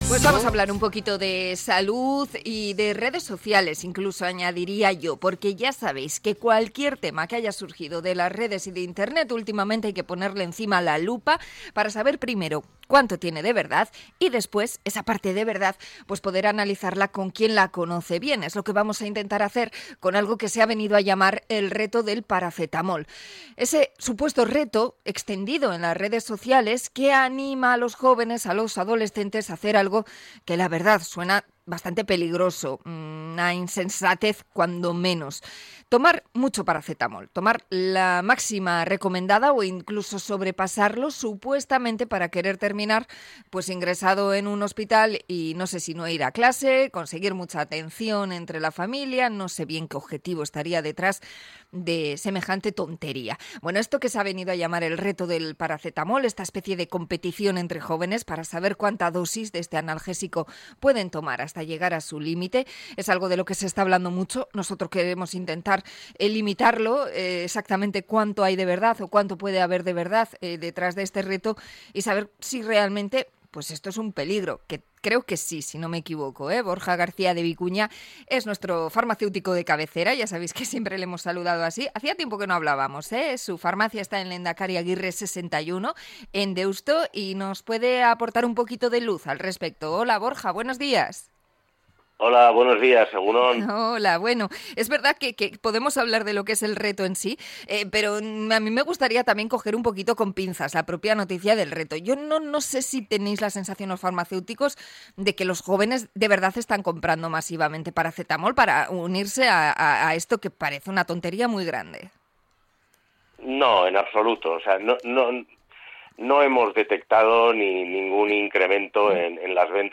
Entrevista a farmacéutico sobre el abuso del paracetamol